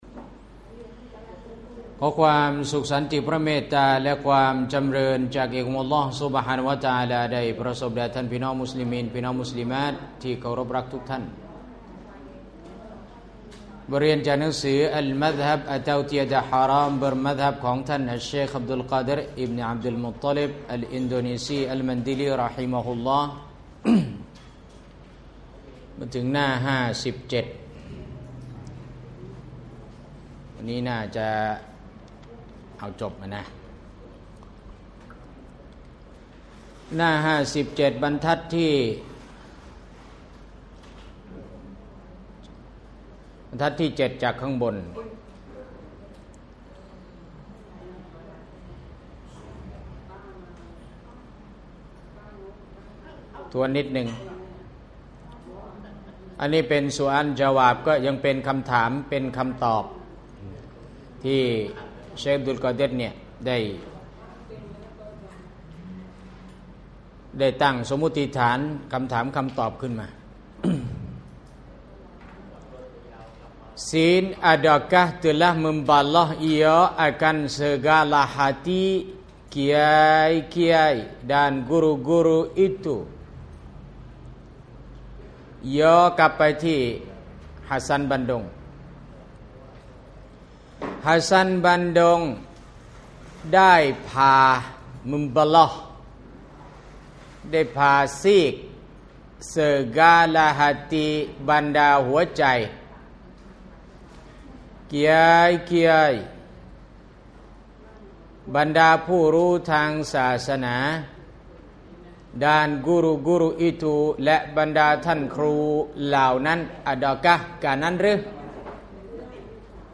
กิตาบ “อัลมัซฮับ” (ไม่ฮะรอมสังกัดมัซฮับ) المذهب : اتو تياد حرام برمذهب ของท่านชัยค์อับดุลกอดิรฺ อัลมันดีลี ร.ฮ. ดาวน์โหลดกิตาบอัลมัซฮับ สถานที่ : บาแลอันนูร สะและมัด ซอยพัฒนาการ 20 แยก 10 ดาวน์โหลดไฟล์เสียง